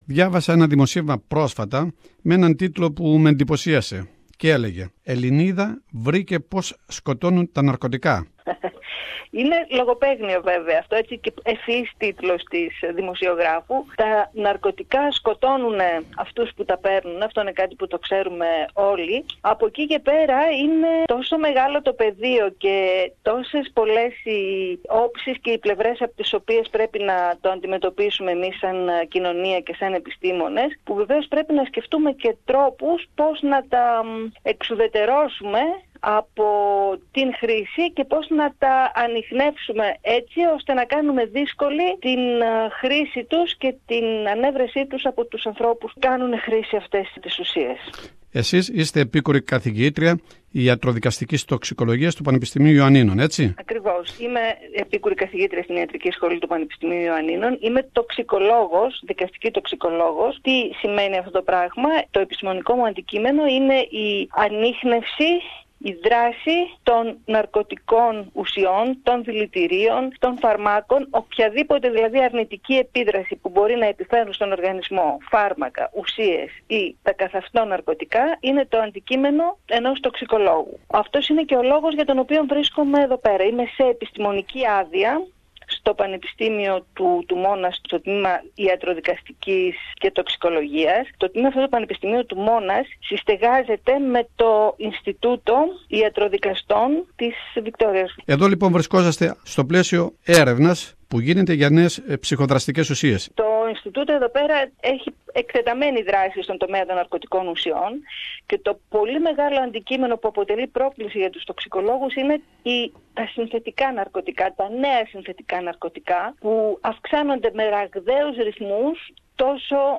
η ελληνίδα επιστήμονας μίλησε